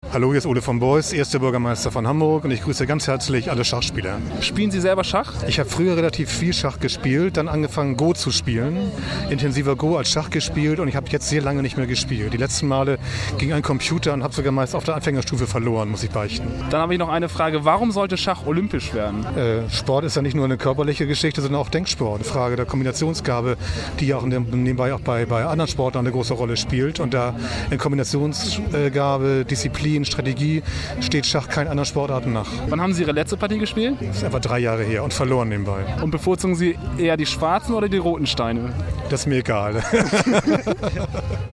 ole v beust interview.mp3